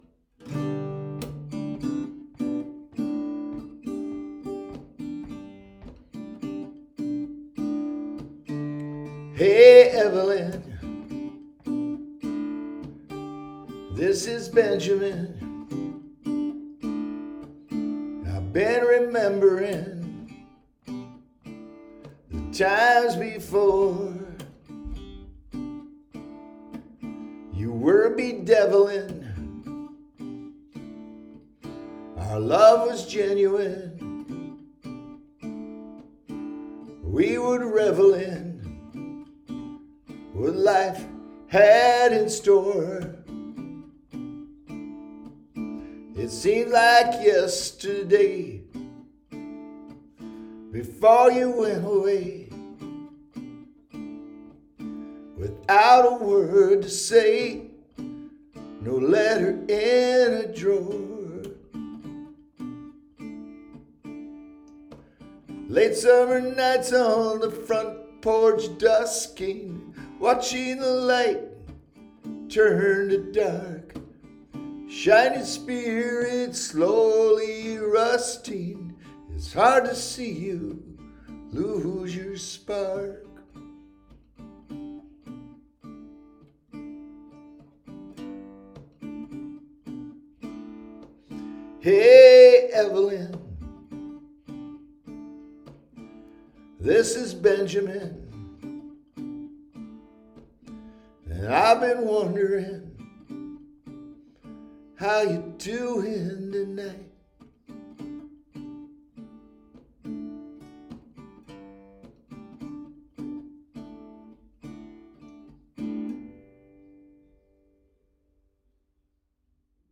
Rather than make a big production out of it, I parked myself in the shower with my guitar and computer and simply recorded it, warts and all.
Evelyn-rough.mp3